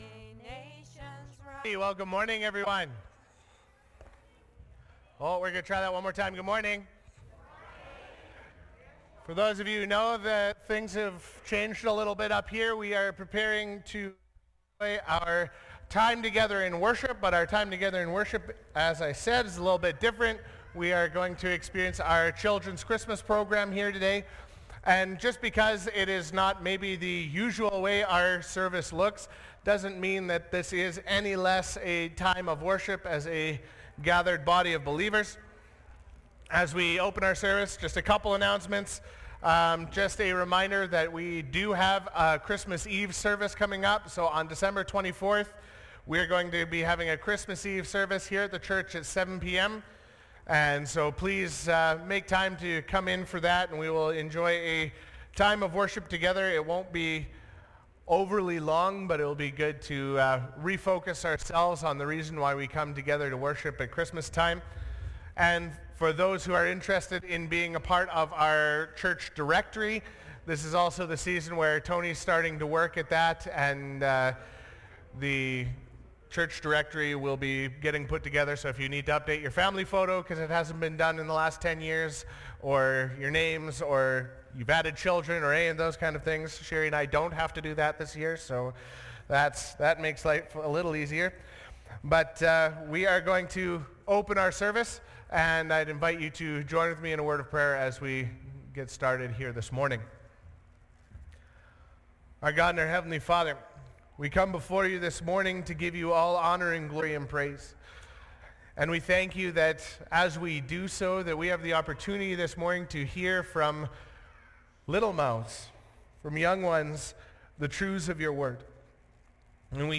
Sunday School Christmas Play